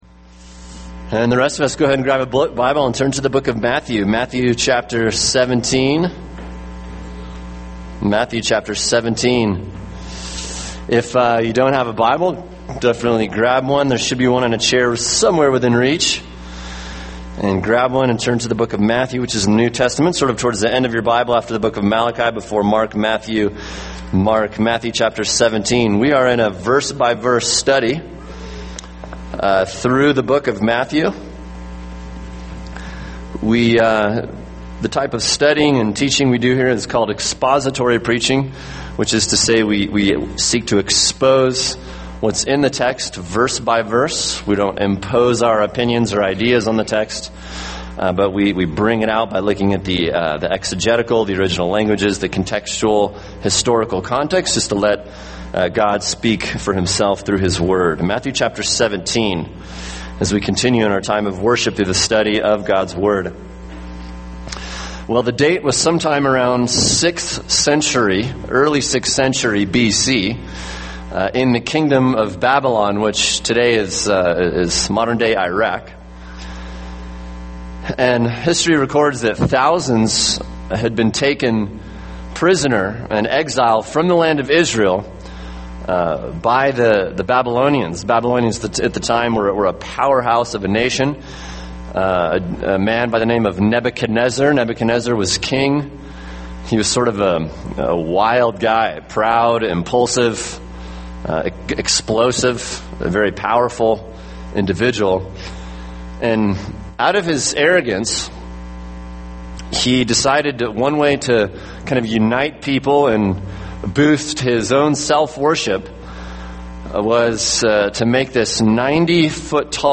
[sermon] Matthew 17:14-23 A Strong Faith | Cornerstone Church - Jackson Hole